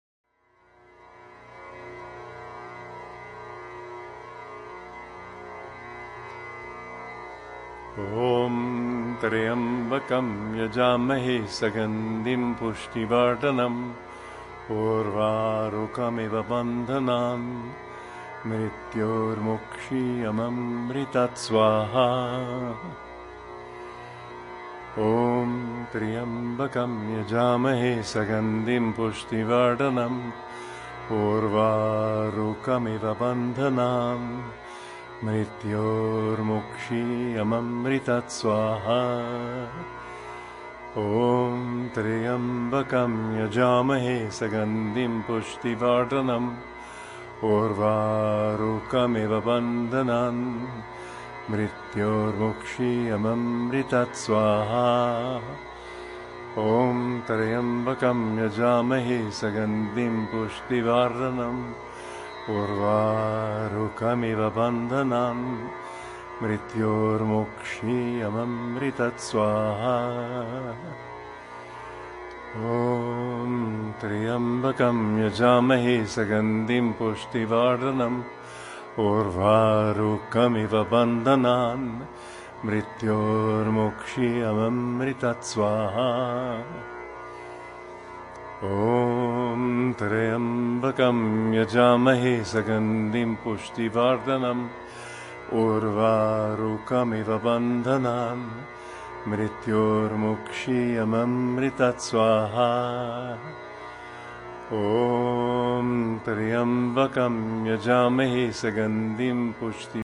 vengono cantati 108 volte
con l’accompagnamento del suono di 120 tanpura